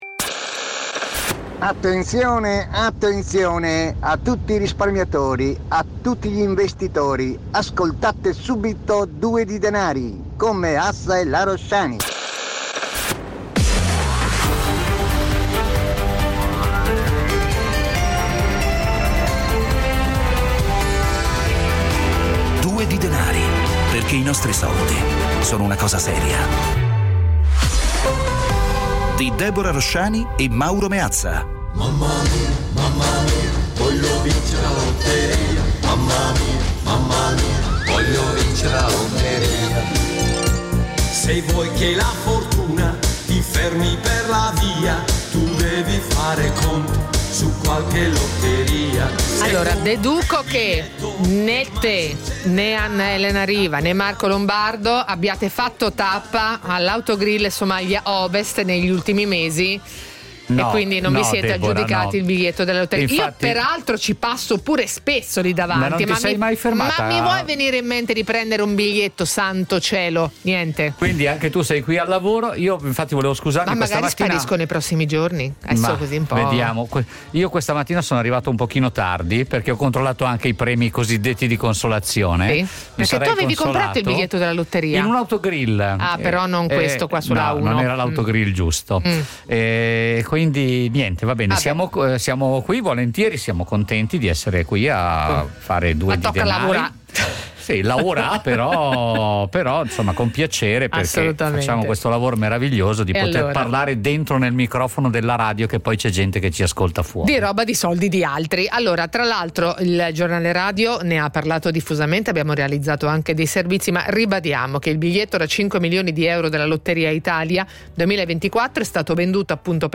Ogni giorno, su Radio 24, in questo spazio vengono affrontati con l'aiuto degli ospiti più competenti, uno sguardo costante all'attualità e i microfoni aperti agli ascoltatori.
La cifra, da sempre, è quella dell’ "autorevoleggerezza" : un linguaggio chiaro e diretto, alla portata di tutti.